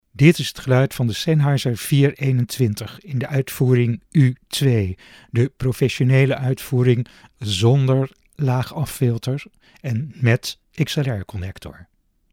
Luister naar de klank van de MD 421
Sennheiser MD 421-U2.mp3